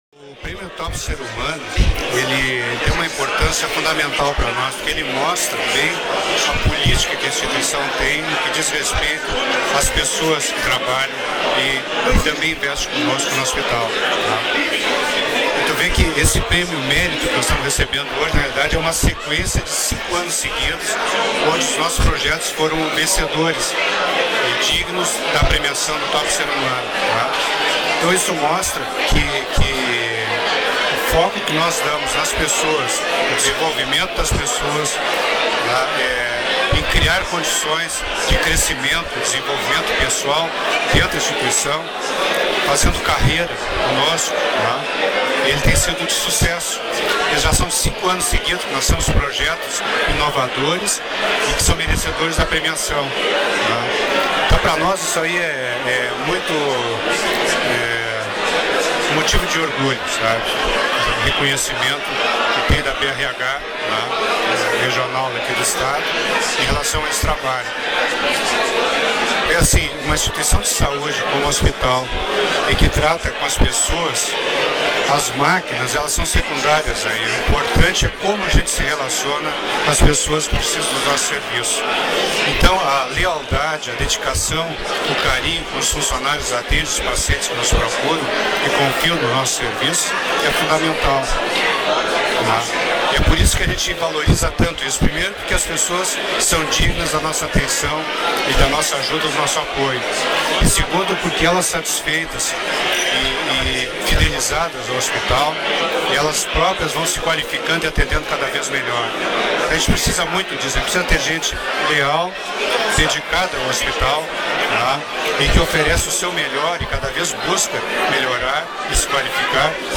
Confira alguns depoimentos: